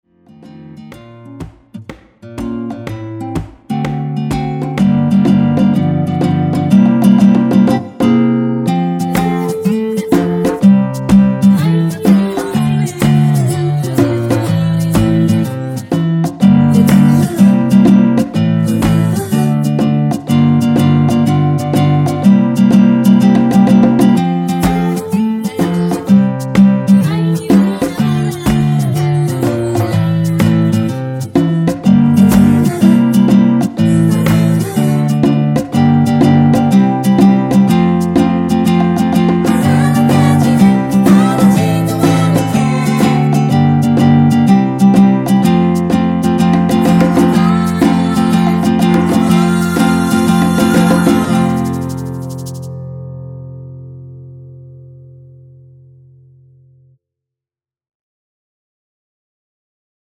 전주 없이 시작 하는곡이라 노래 하시기 편하게 전주 2마디 많들어 놓았습니다.(일반 MR 미리듣기 확인)
원키에서(+1)올린 코러스 포함된 MR입니다.(미리듣기 확인)
앞부분30초, 뒷부분30초씩 편집해서 올려 드리고 있습니다.